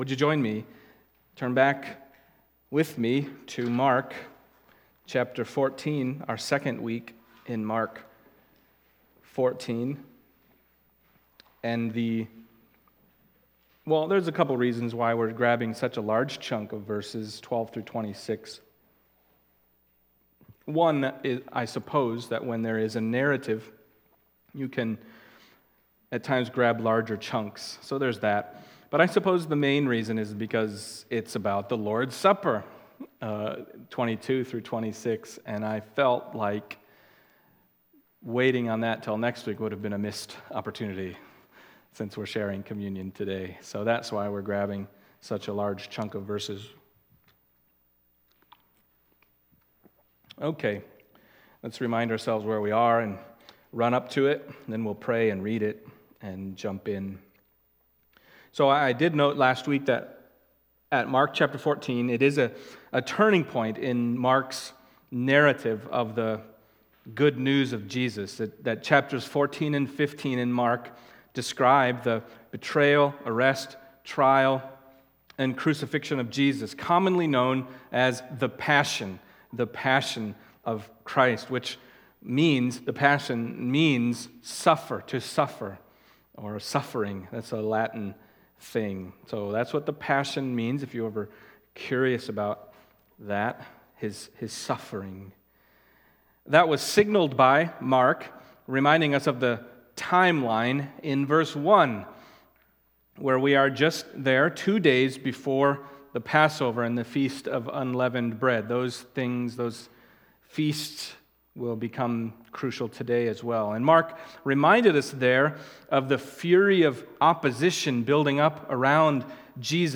Mark Passage: Mark 14:12-26 Service Type: Sunday Morning Mark 14:12-26 « Treasuring Christ